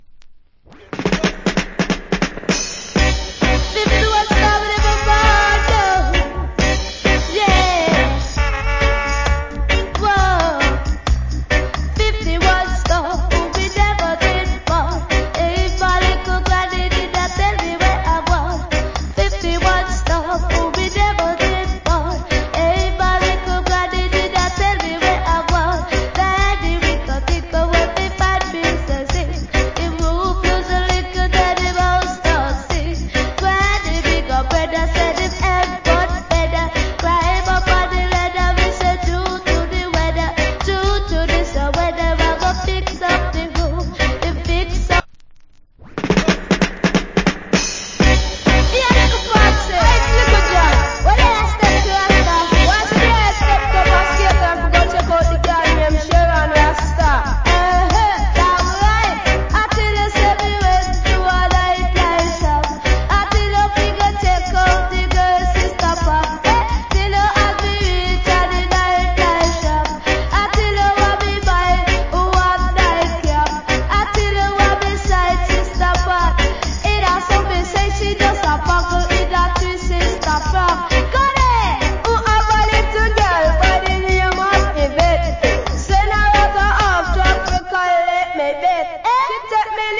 Killer DJ.